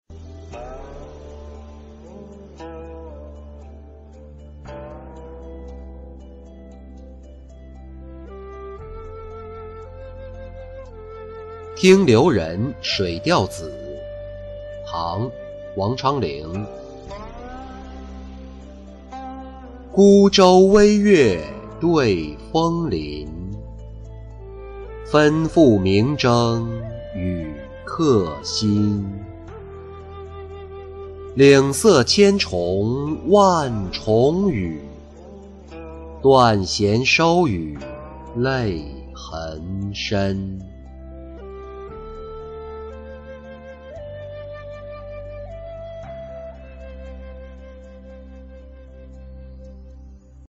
听流人水调子-音频朗读